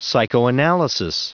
Prononciation du mot psychoanalysis en anglais (fichier audio)
Prononciation du mot : psychoanalysis